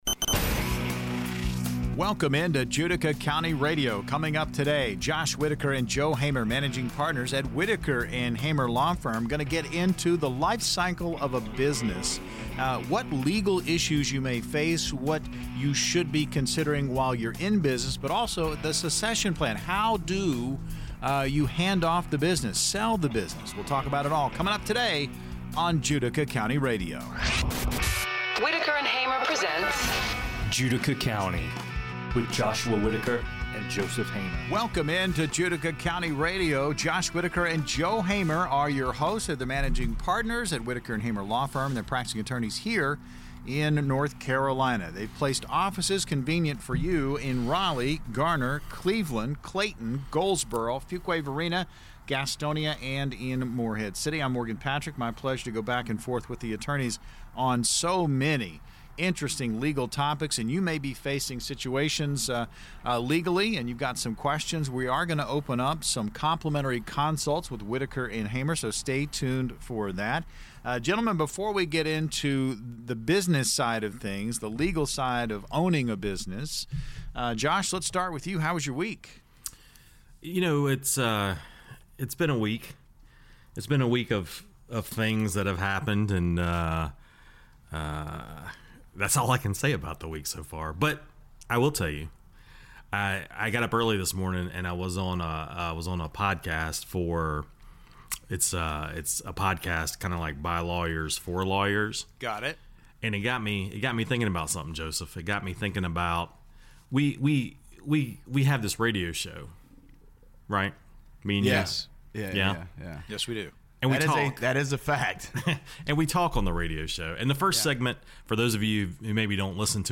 In this conversation, the lawyers discuss the critical legal considerations for business owners as they navigate the lifecycle of their business. They emphasize the importance of having proper legal documentation, understanding employee classifications, and preparing for potential transitions or succession planning.